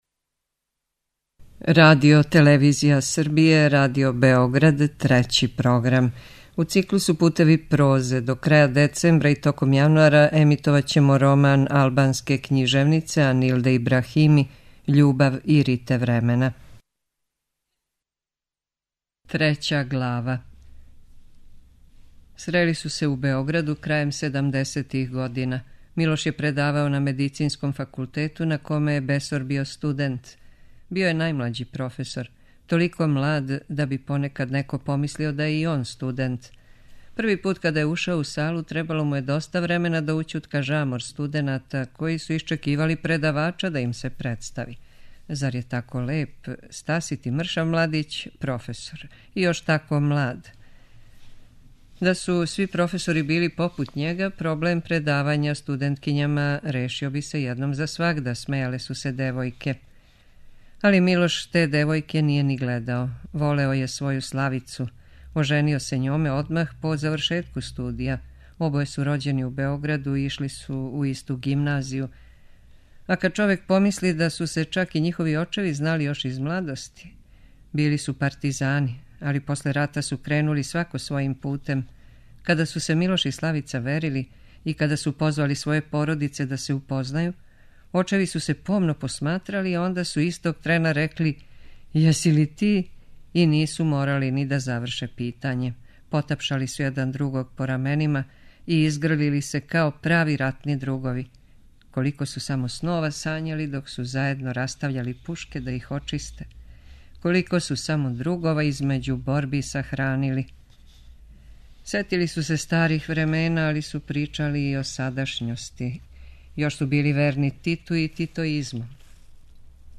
преузми : 6.99 MB Књига за слушање Autor: Трећи програм Циклус „Књига за слушање” на програму је сваког дана, од 23.45 сати.